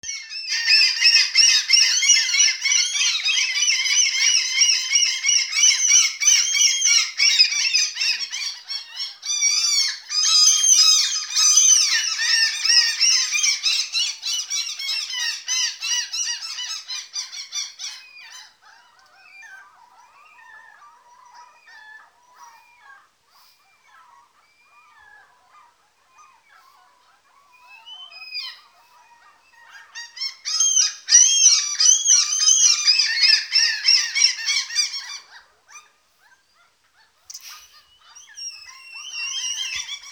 Spaní je v lofotském létě poměrně zajímavý zážitek - kromě toho, že je celou noc světlo téměř jako přes den, tak vám nad stanem neustále krouží místní hejna racků, kteří vydávají
takovéto zvuky, a to tak hlasitě, jako by se vám uhnízdili přímo za hlavou.
lofoten-rackove.wav